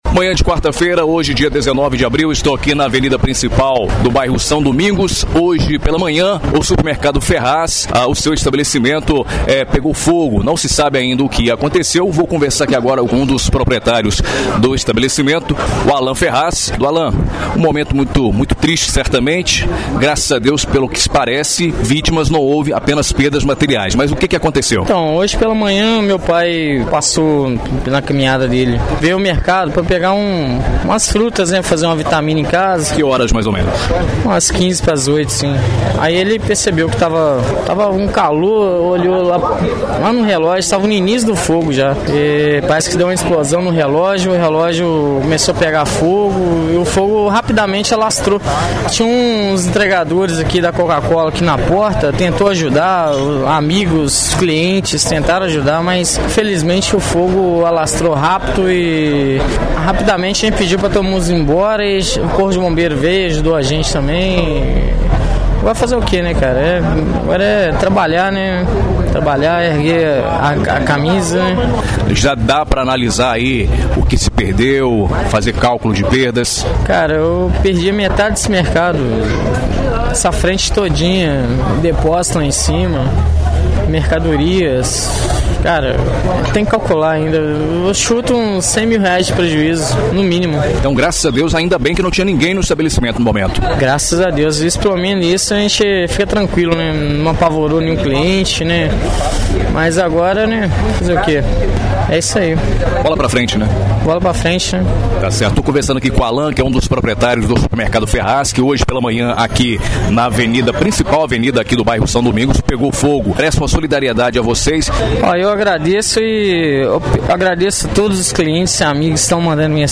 Ouça a Reportagem do dia 19 de abril de 2017 – exibida na Rádio Educadora AM/ FM no Jornal em Dia com a Notícia